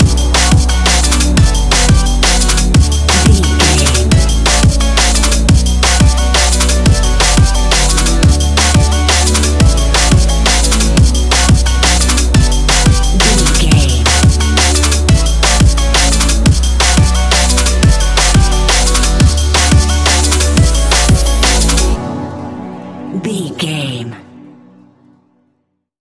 Ionian/Major
B♭
techno
trance
synthesizer
synthwave
glitch